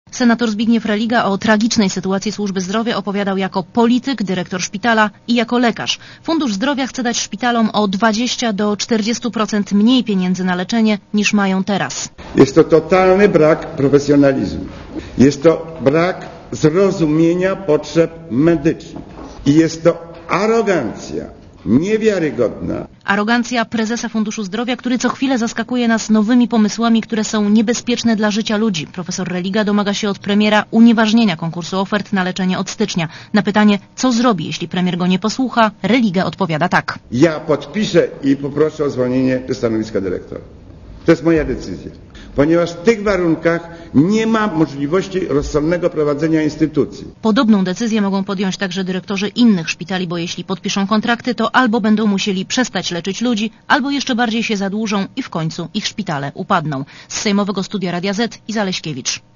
Posłuchaj relacji reportera Radia Zet (232Kb)
Senator Religa przedstawił na konferencji prasowej swoją ocenę sytuacji w opiece zdrowotnej.